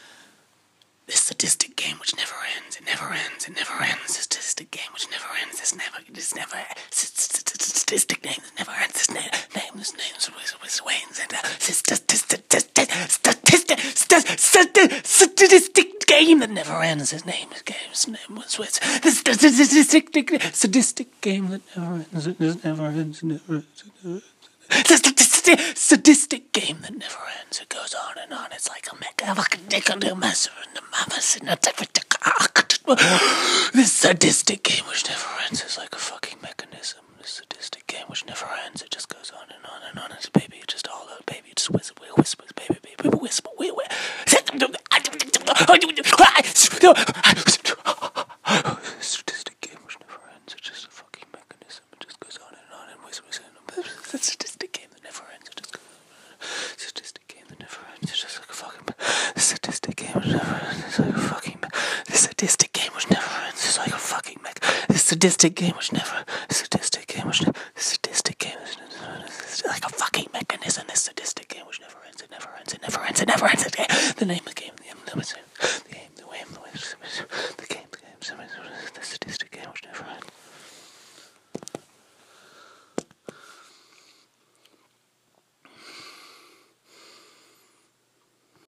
Spoken Word